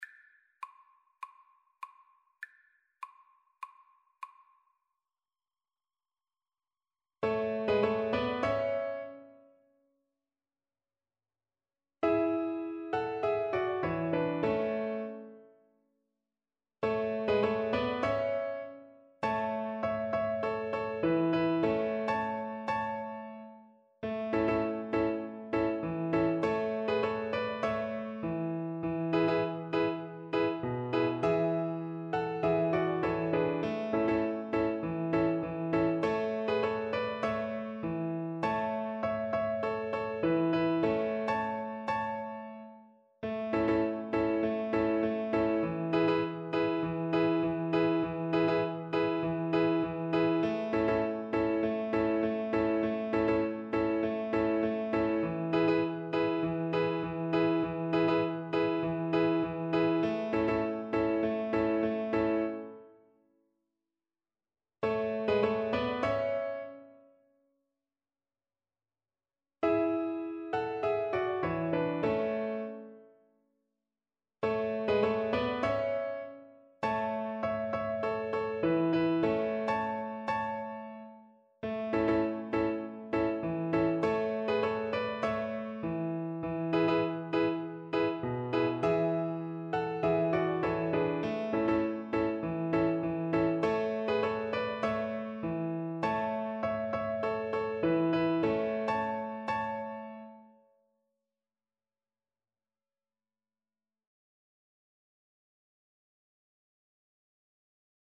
Play (or use space bar on your keyboard) Pause Music Playalong - Piano Accompaniment Playalong Band Accompaniment not yet available transpose reset tempo print settings full screen
Violin
4/4 (View more 4/4 Music)
A major (Sounding Pitch) (View more A major Music for Violin )
Moderato
Caribbean Music for Violin